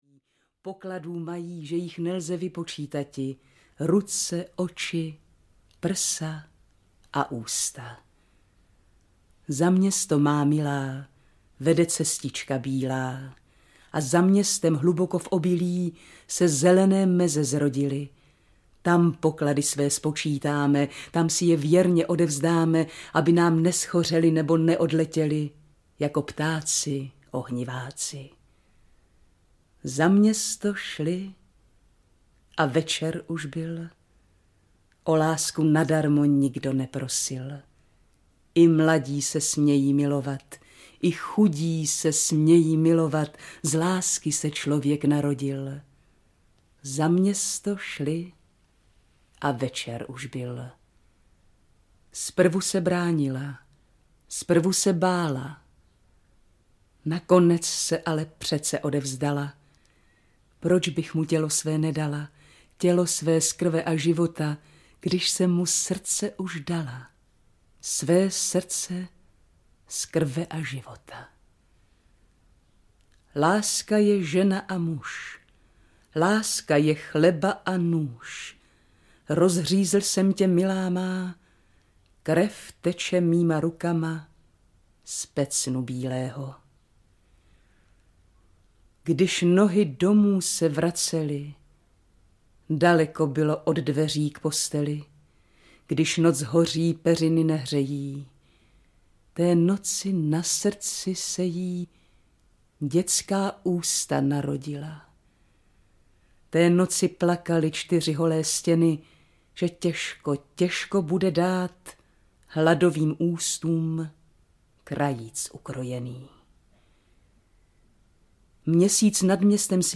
Těžká hodina audiokniha
Ukázka z knihy